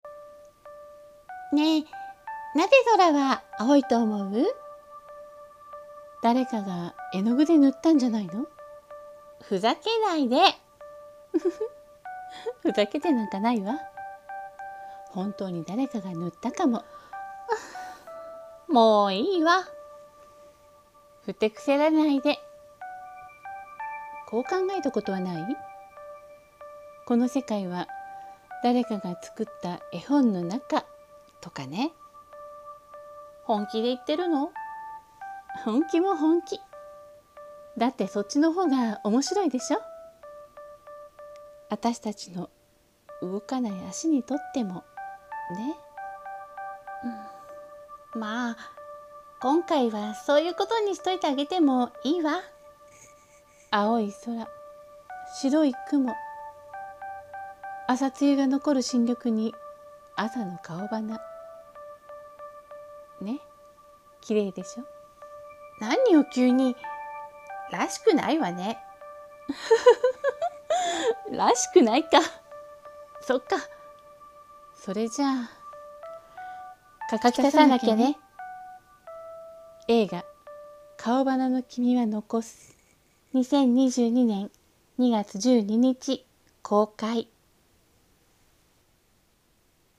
【映画予告風】容花の君は残す【2人声劇】